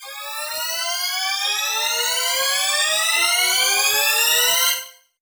CDK Transition 3.wav